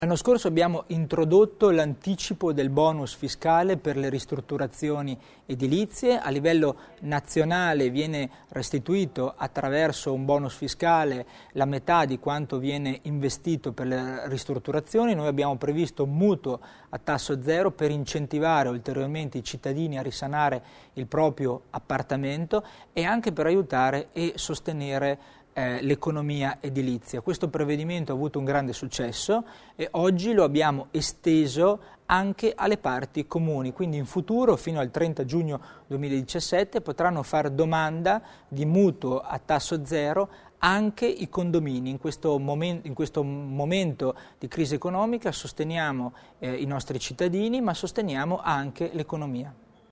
Il Vicepresidente Tommasini illustra i dettagli dell'anticipo del bonus fiscale